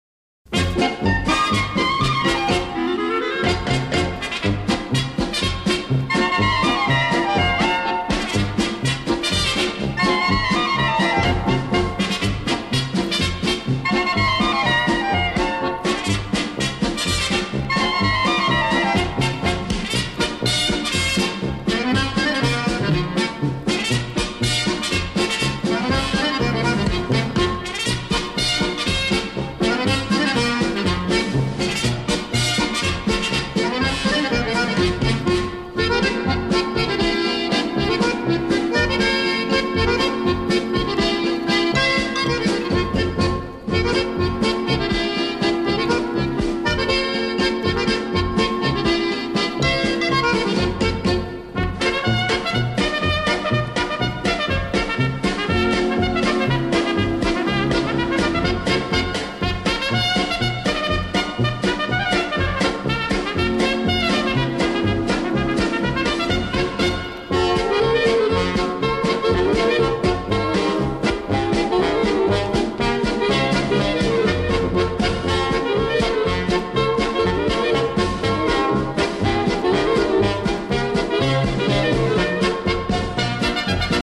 Рытм востры, з частымі сінкопамі. Выконваецца весела, тэмпераментна, з ганарлівай выправай.